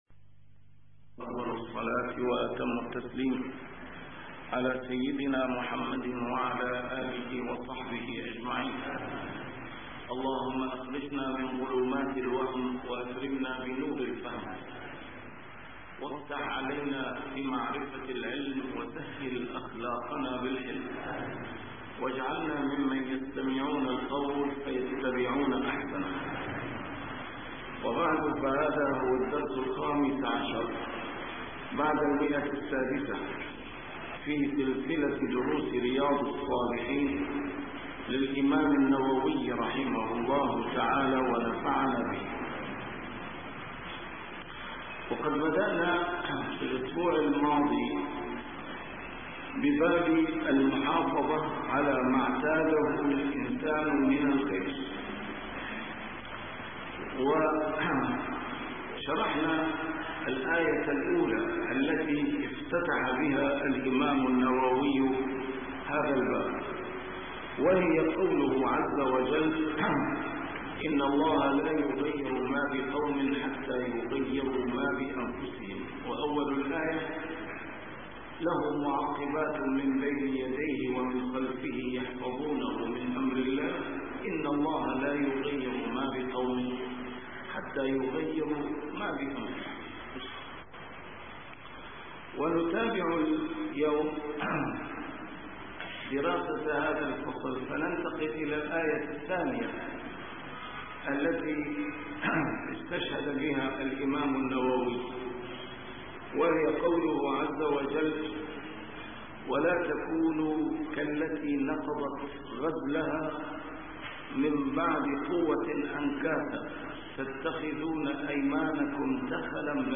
A MARTYR SCHOLAR: IMAM MUHAMMAD SAEED RAMADAN AL-BOUTI - الدروس العلمية - شرح كتاب رياض الصالحين - 615- شرح رياض الصالحين: المحافظة على ما اعتاده من الخير